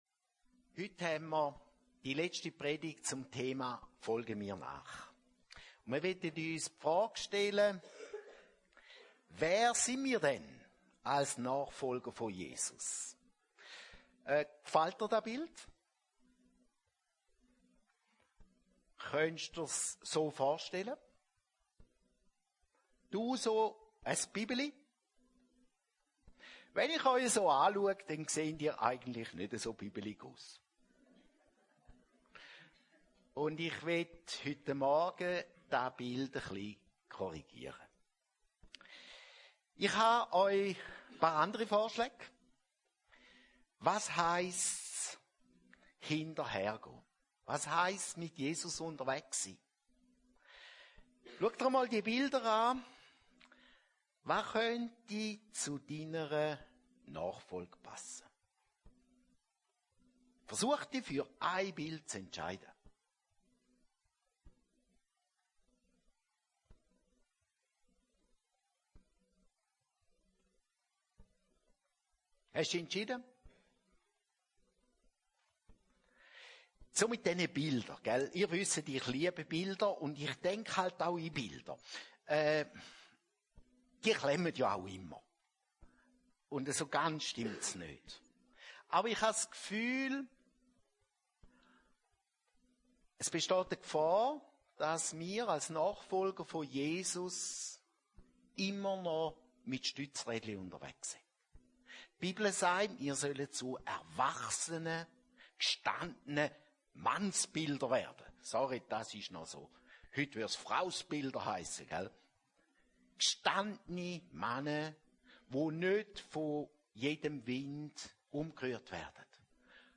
180304_predigt.mp3